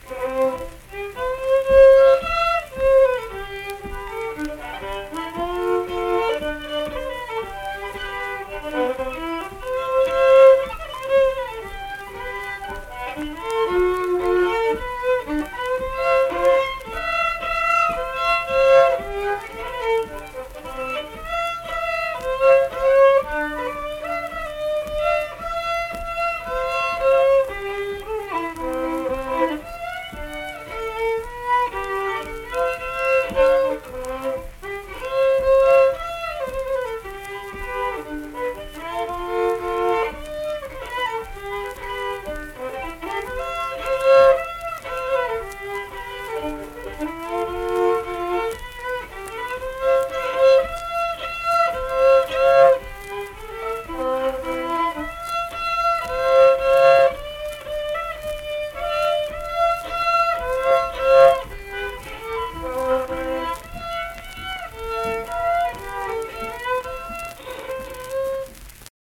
Unaccompanied fiddle performance
Instrumental Music
Fiddle
Tyler County (W. Va.), Middlebourne (W. Va.)